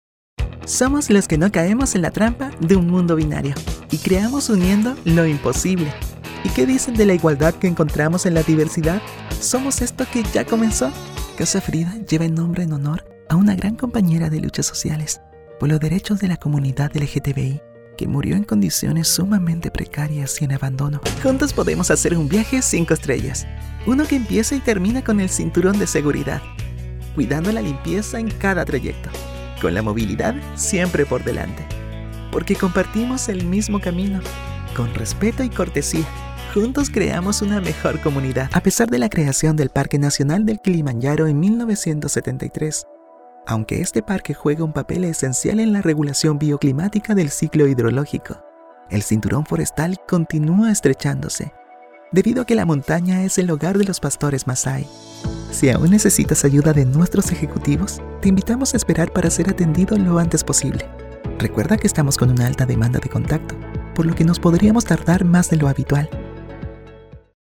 Demonstração Comercial
Microfone Neumann TLM 103
Estúdio doméstico
Jovem adulto